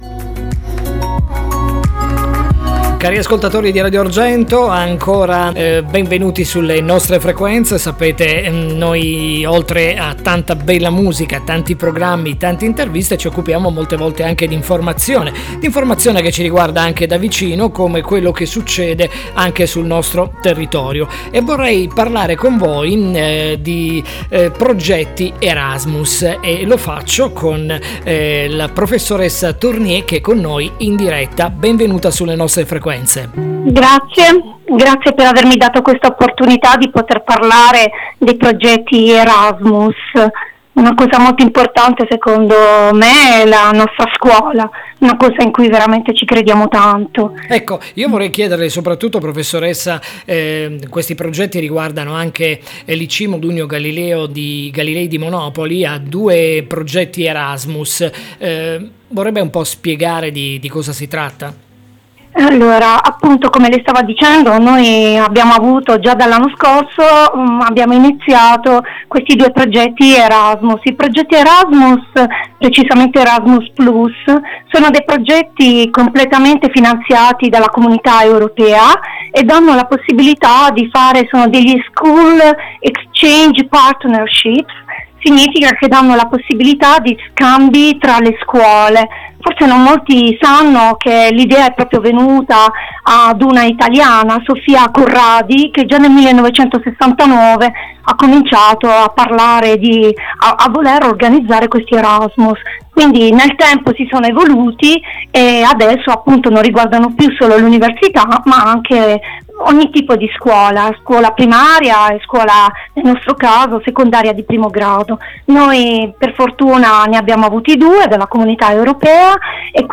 Radio Interview from Radio Argento